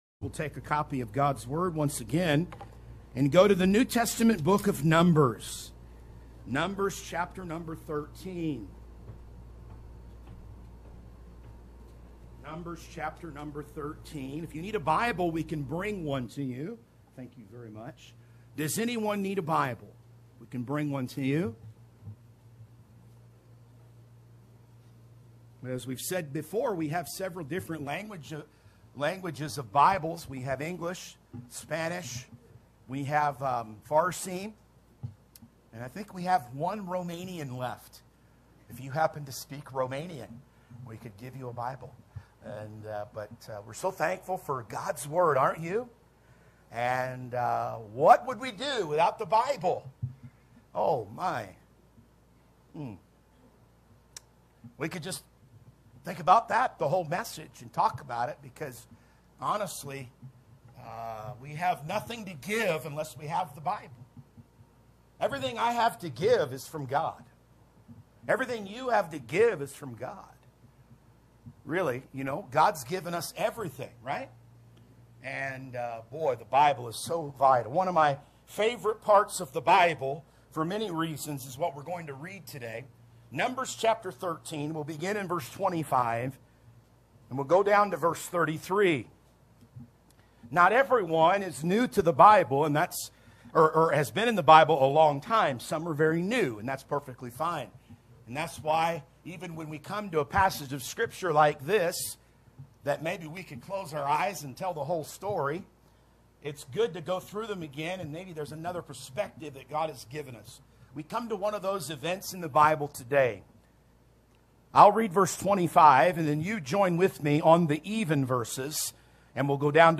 Sermons | Anchor Baptist Church
Sunday 1030AM August 11 2024 Preaching